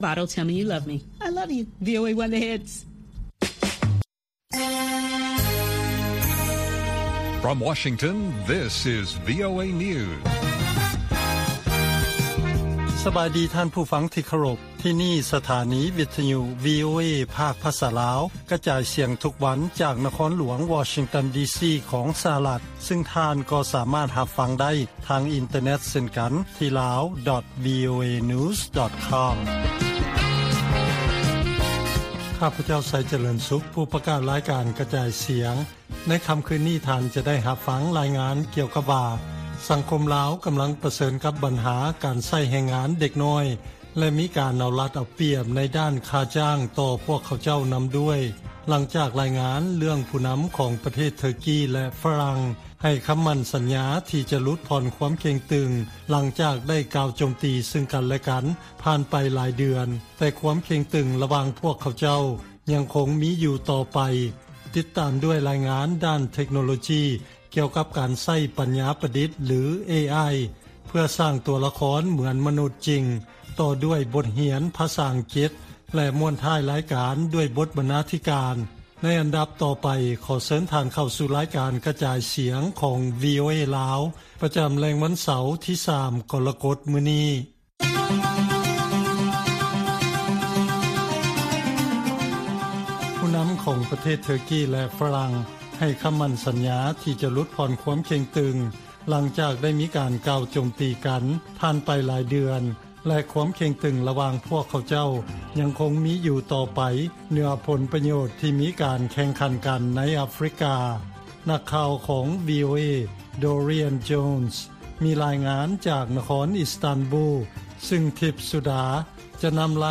ລາຍການກະຈາຍສຽງຂອງວີໂອເອ ລາວ: ເທີກີ ແລະຝຣັ່ງຫລຸດຜ່ອນຄວາມເຄັ່ງຕຶງ, ແຕ່ການແຂ່ງຂັນໃນອາຟຣິກາຍັງຄົງມີຢູ່